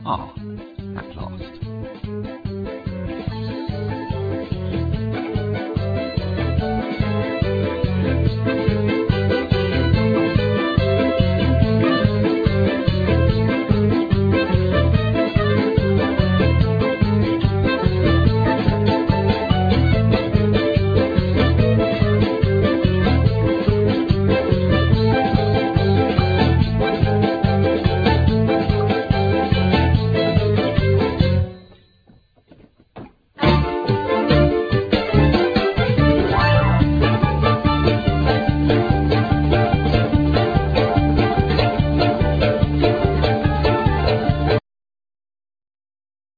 Guitar,Bouzouki,Veena,Voice
Drums,Percussion,Marimba,Voice
Keyboards,Accordion,Melodica,Voice
Bass,Voice